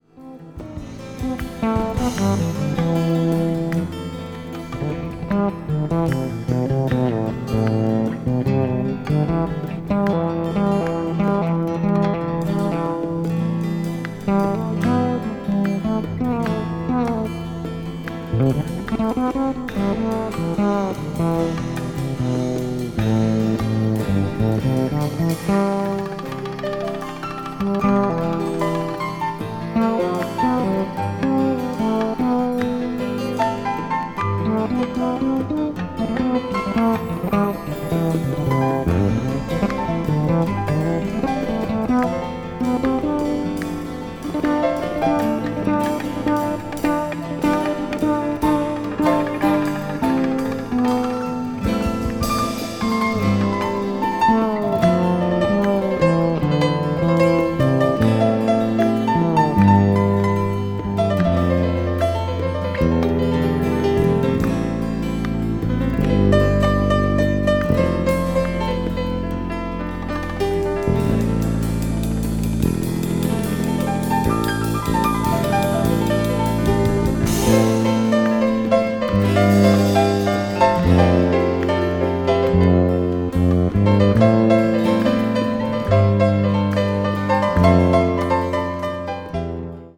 ケルトの伝統音楽をアレンジしたA3はEberhard WeberのECM作品に通じるセレッシャルな世界。
crossover   ethnic jazz   fusion   world music